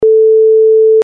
pic 1 Amplitude and frequency for a typical waveform (sine).
sine440.mp3